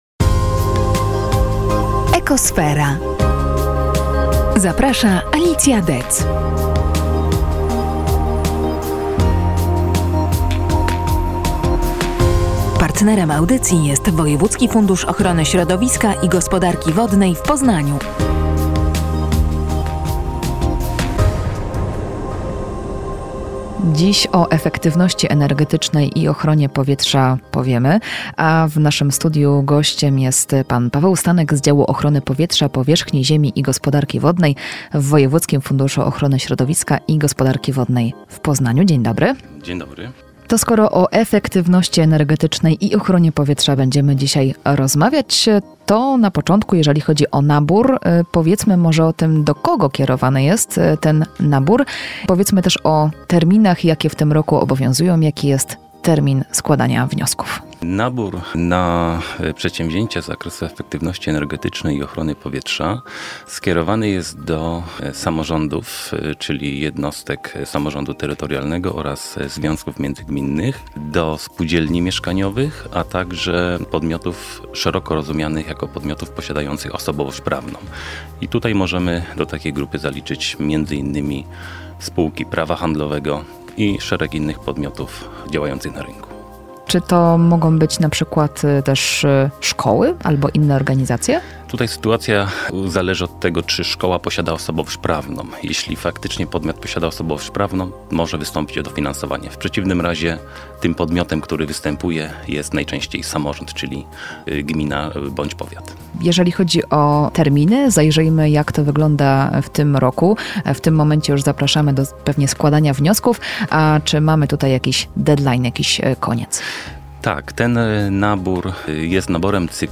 Wczoraj na antenie radia EMAUS miała miejsce audycja „Ekosfera”.